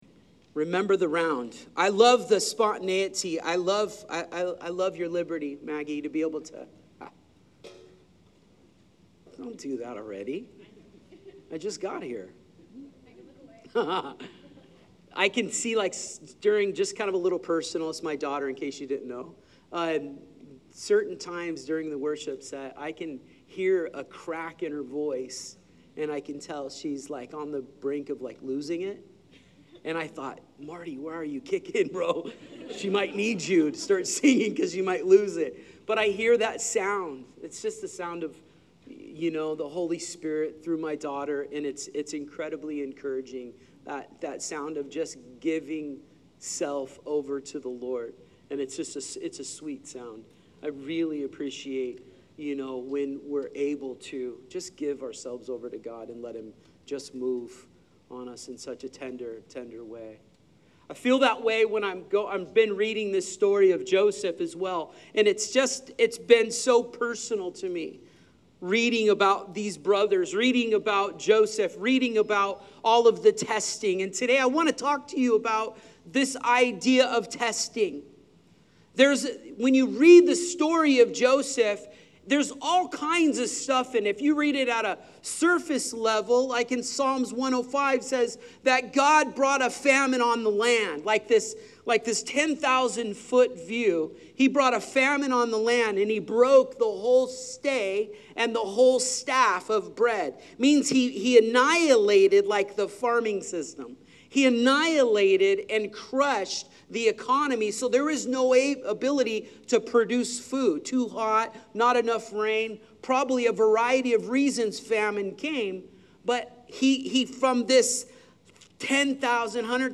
Sermon Series: God Meant It for Good — The Journey of Joseph